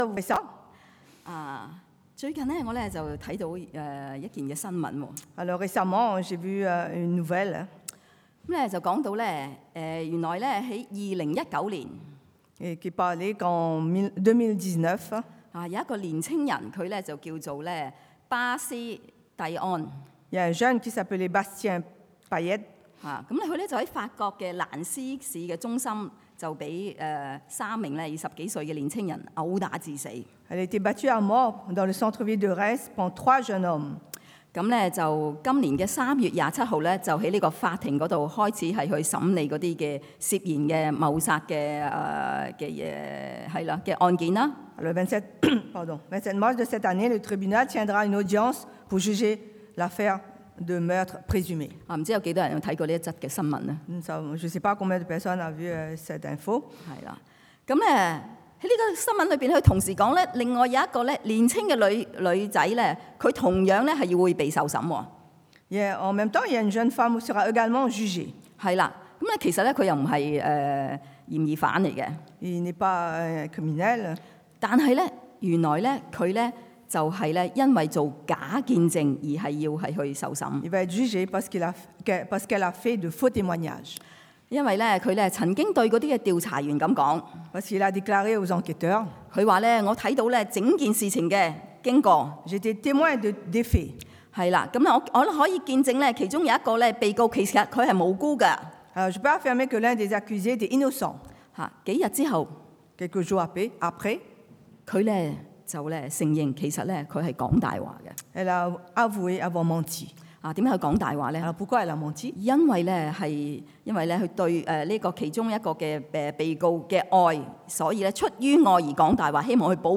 Predication du dimanche